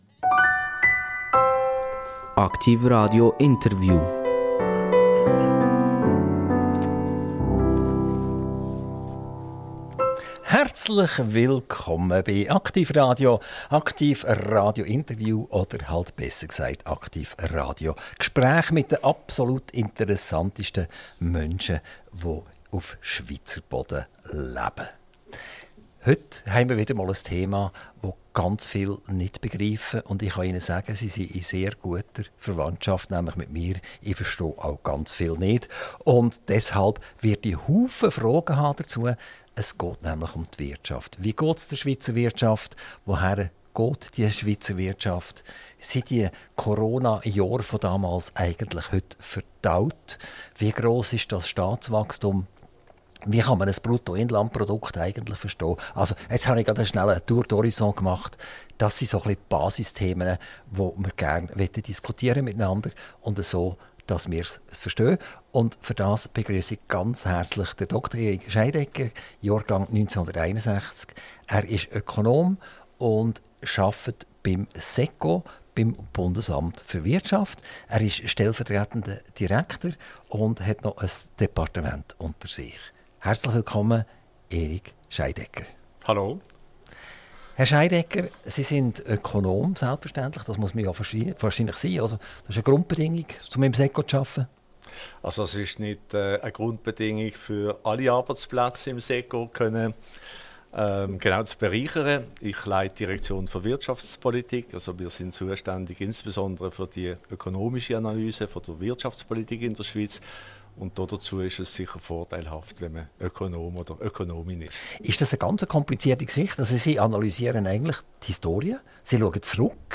Interview - Dr. Eric Scheidegger - 28.05.2024 ~ AKTIV RADIO Podcast